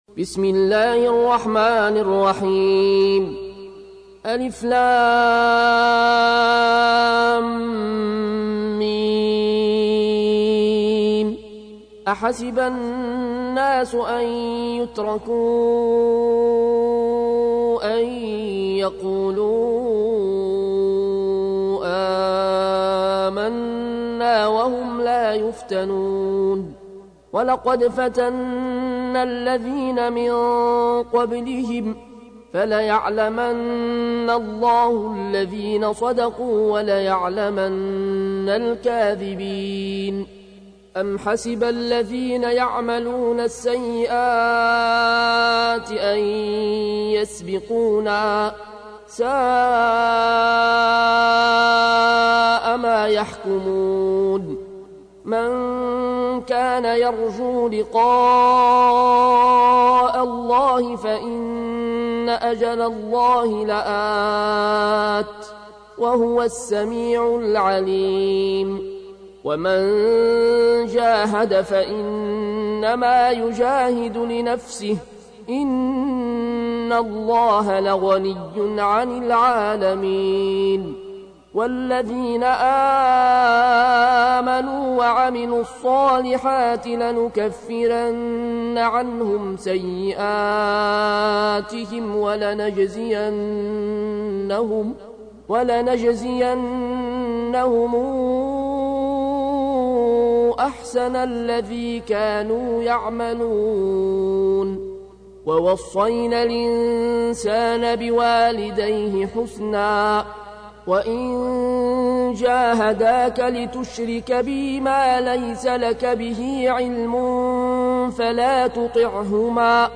تحميل : 29. سورة العنكبوت / القارئ العيون الكوشي / القرآن الكريم / موقع يا حسين